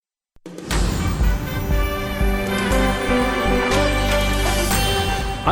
பிபிசி தமிழோசை உலகச் செய்தியறிக்கை (22.01.2018)